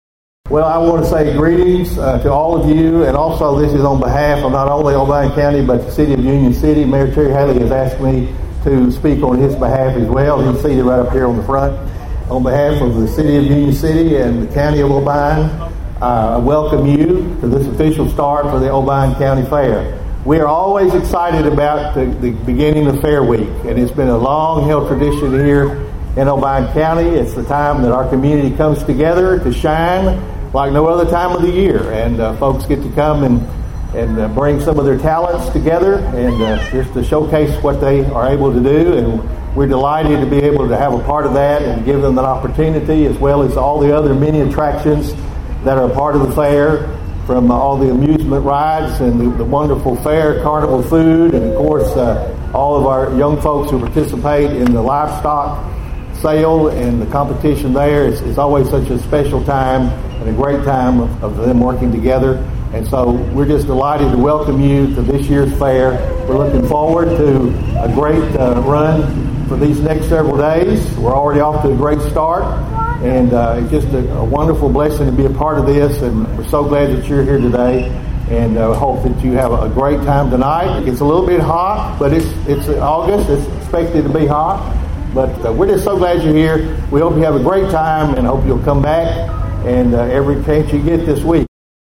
The Obion County Fair held its official opening ceremonies last night on the Main Stage.
Obion County Mayor Steve Carr delivered the official welcome to start the events and activities for the week.(AUDIO)